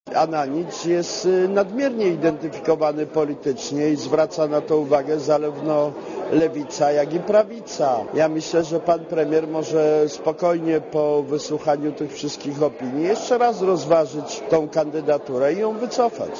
Posłuchaj komentarza Krzysztofa Janika